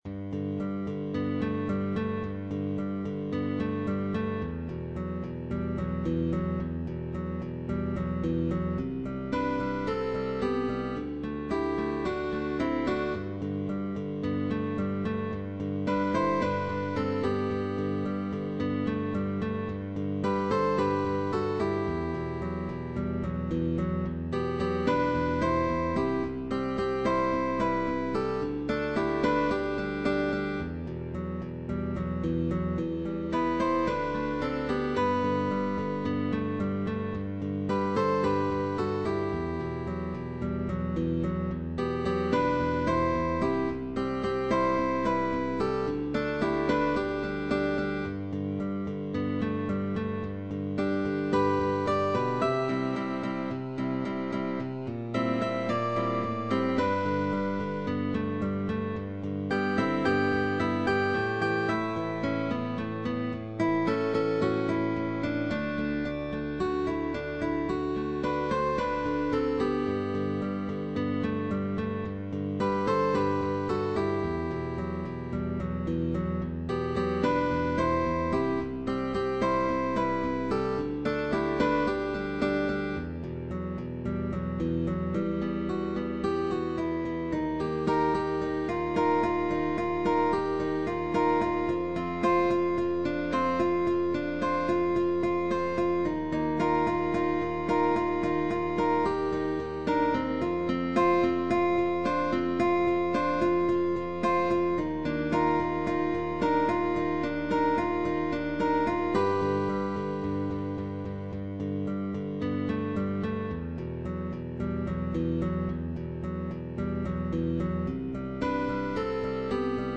With optional bass, also valid for guitar orchestra.
(Optional bass)